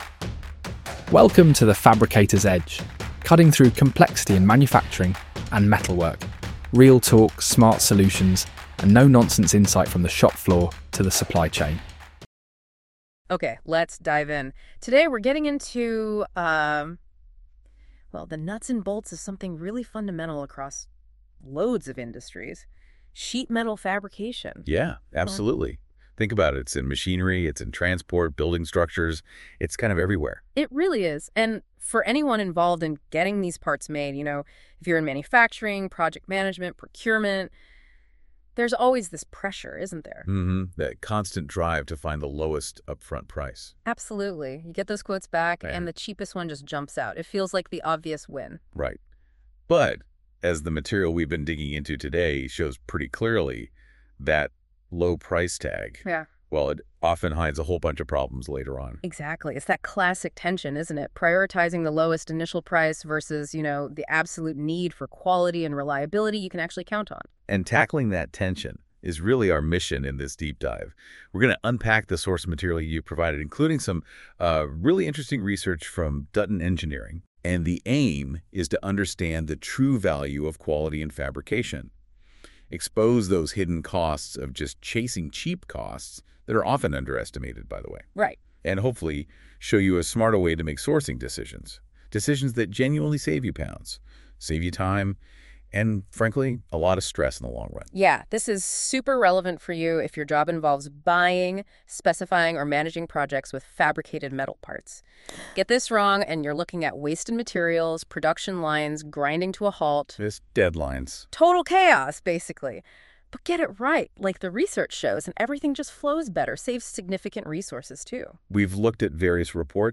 Welcome to Episode 2 of The Fabricator’s Edge — a new podcast from the team at Dutton Engineering.